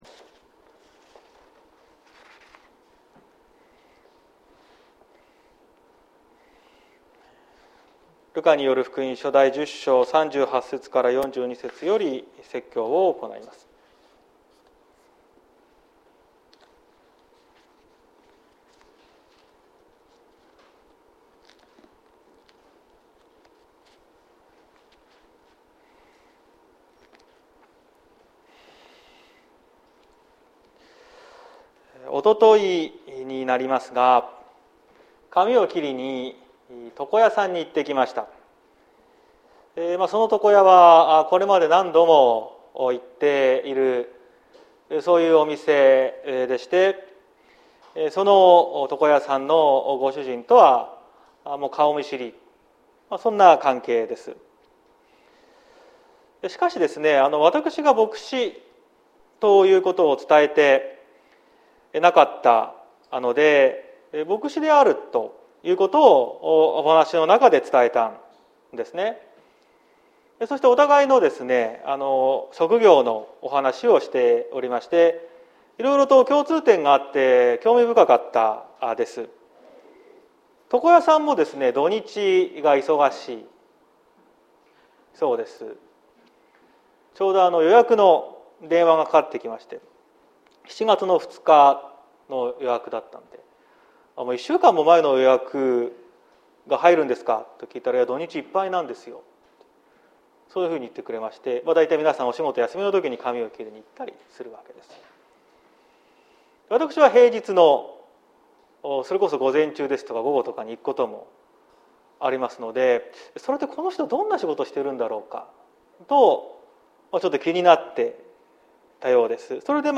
2022年06月26日朝の礼拝「何を聞くのか」綱島教会
説教アーカイブ。
毎週日曜日の10時30分から神様に祈りと感謝をささげる礼拝を開いています。